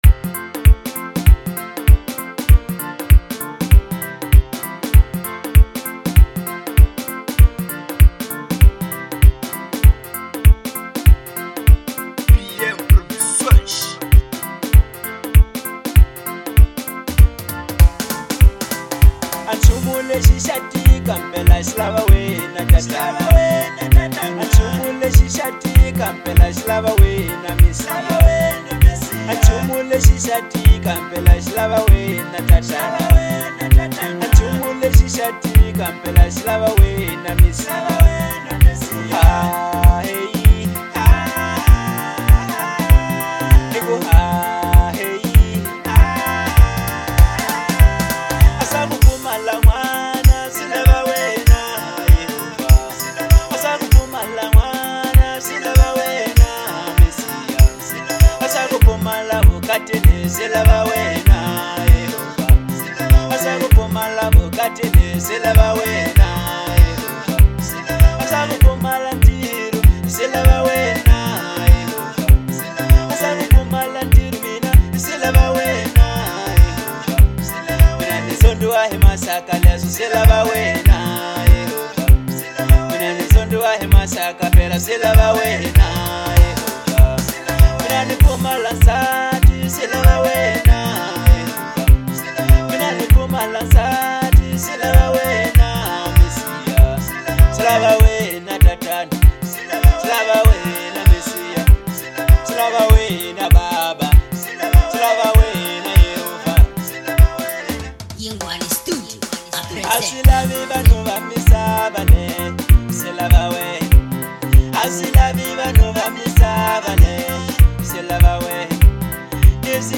05:28 Genre : Marrabenta Size